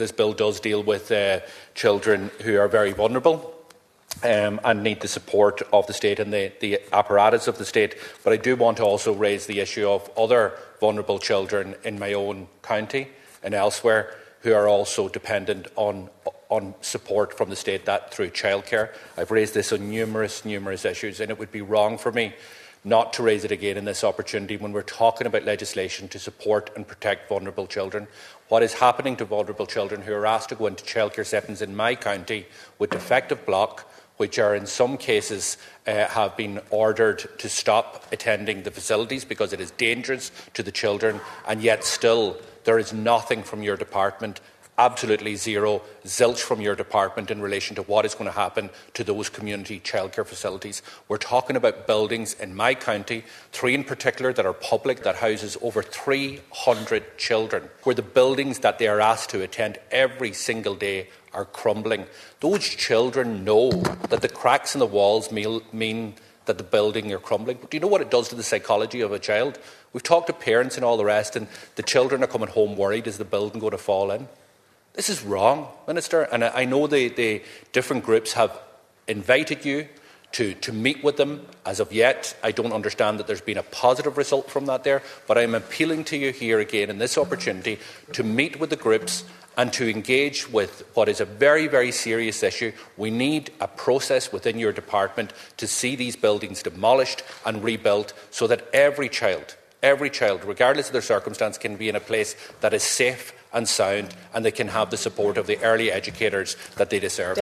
The issue was raised by Donegal Deputy Pearse Doherty during a debate on the second stage of the Child Care Amendment Bill 2025.